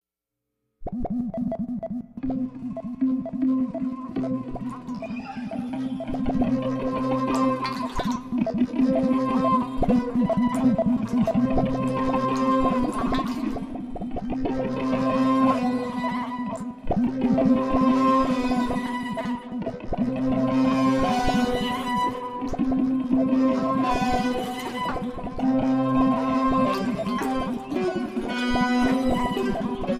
Electric Guitar and SuperCollider
Electronics